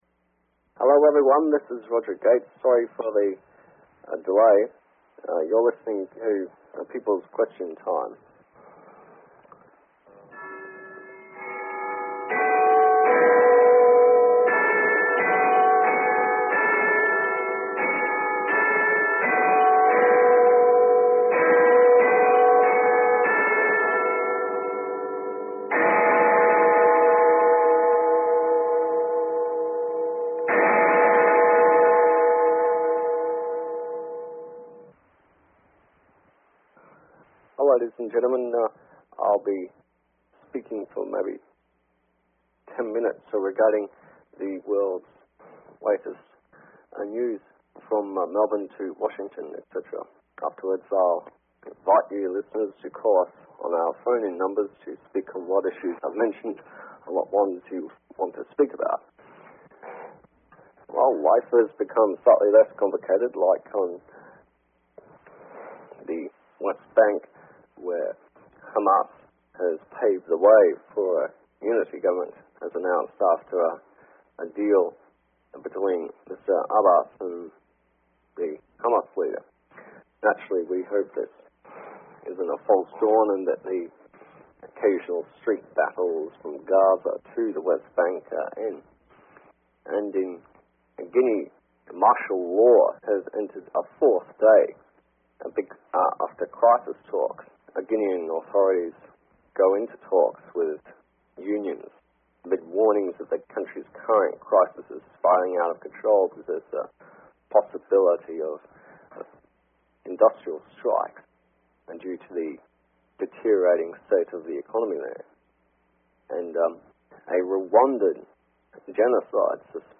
Talk Show Episode, Audio Podcast, Peoples_Question_Time and Courtesy of BBS Radio on , show guests , about , categorized as